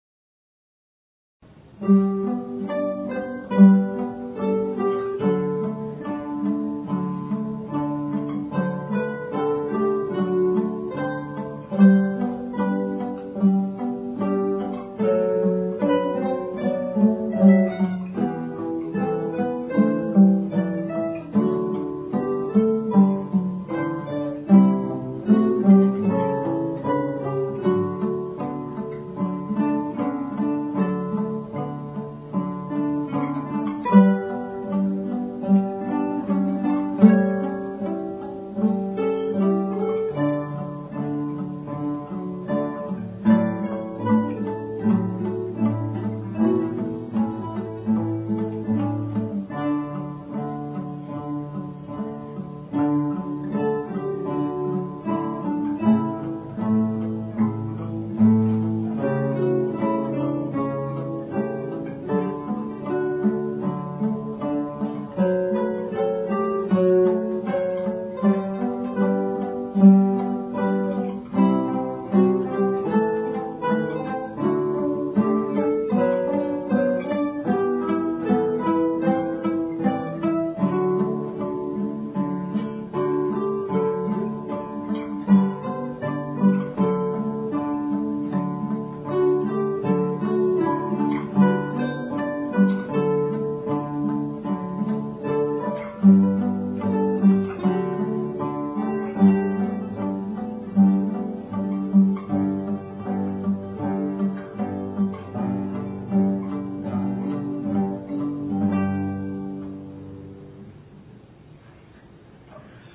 アンサンブルｐｉｍａ（府大クラシックギタークラブＯＢ有志）
練習風景（２００３年０９月１５日撮影）　クラブ同期の有志６人で３５年ぶりに一泊二日で合奏をやりました。